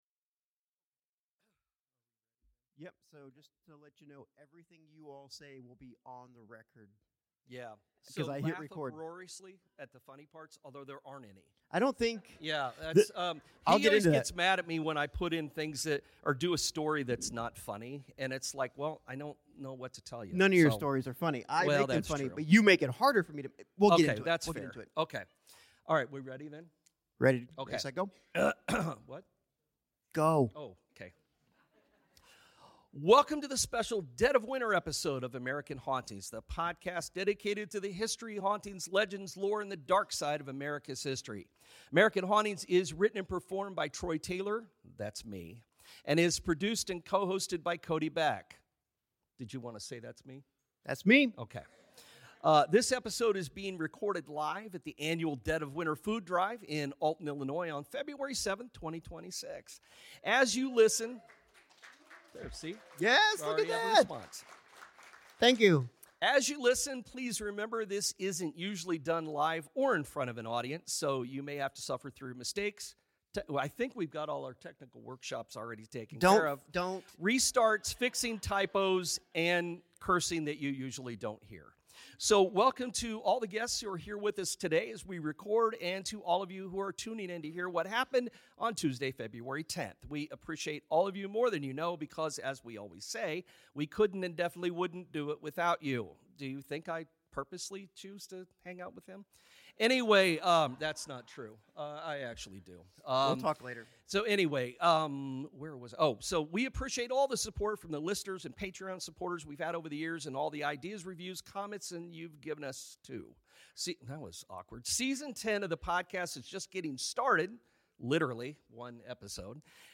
Live from Dead of Winter 2026